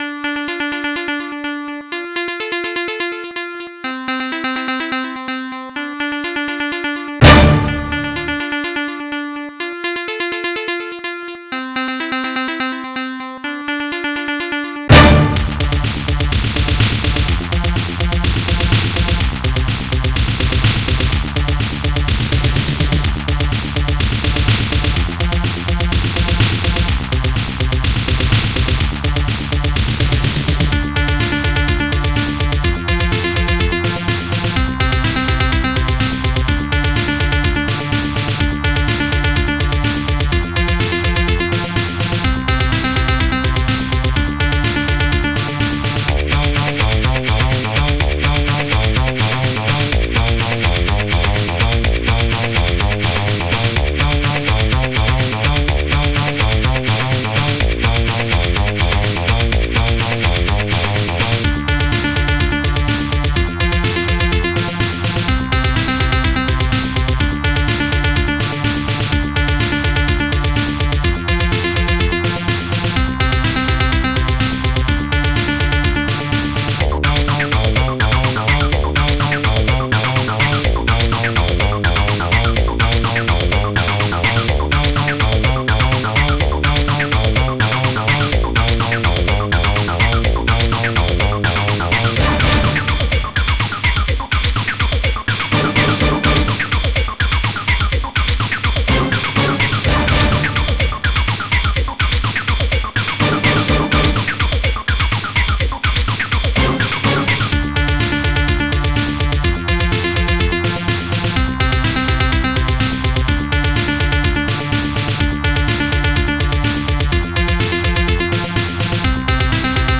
ST-01:monobass
ST-04:powerbassdrum
ST-03:snaredrum2
ST-01:rubberbass
ST-03:claps2
ST-01:steinway
ST-03:orch.hit
ST-01:shaker